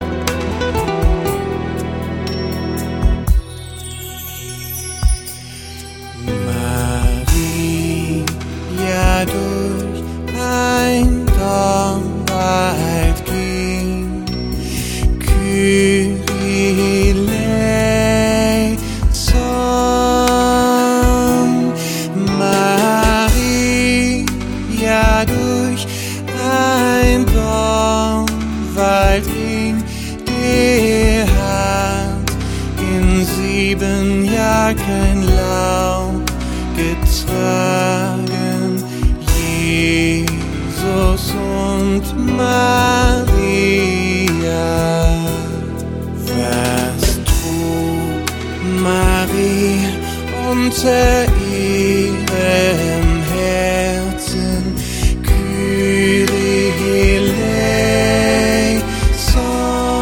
Instrumental, Kinderlieder